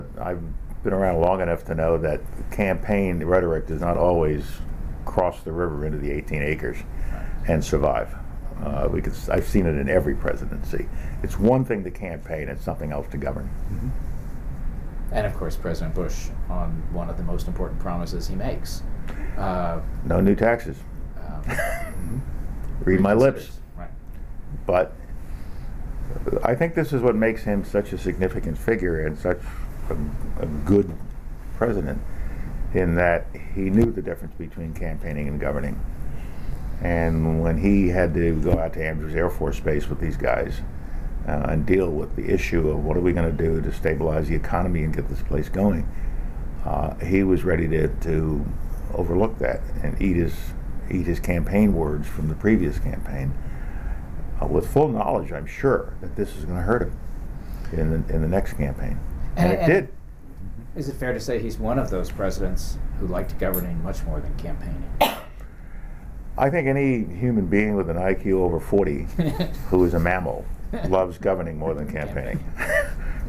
'Campaigning versus Governing' Photo: Ronald Reagan Presidential Library Colin Powell, chairman of the Joint Chiefs of Staff for President George H. W. Bush, discusses campaigning versus governing in an effective presidency. Date: December 16, 2011 Participants Colin Powell Associated Resources Colin Powell Oral History The George H. W. Bush Presidential Oral History Audio File Transcript